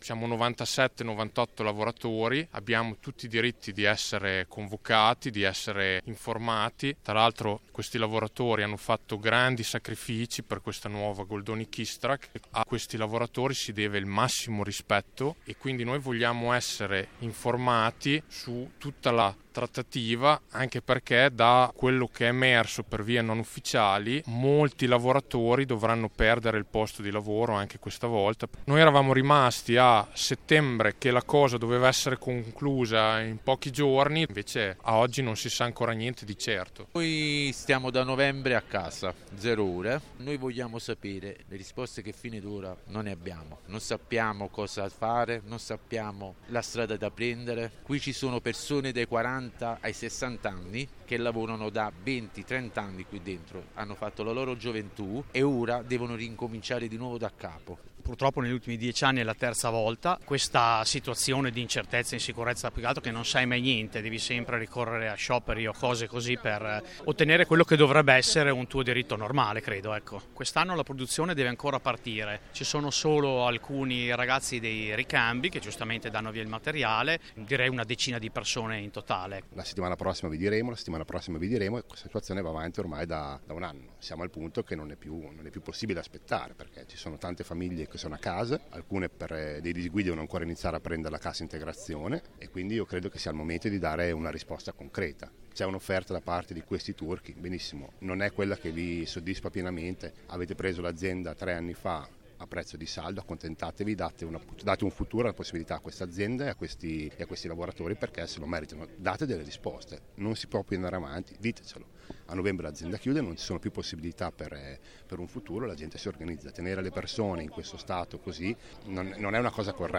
Sciopero di due ore questa mattina, lunedì 23 febbraio, con presidio davanti ai cancelli della Goldoni contro la mancanza di risposte e di informazioni, che si protrae da mesi, sullo stato di avanzamento della trattativa per il subentro del Gruppo turco nello stabilimento di Migliarina, dal momento che la proprietà, l’azienda belga Keestrack, ha dichiarato di non avere più la capacità economica per sostenere l’attività produttiva.
La parola ai lavoratori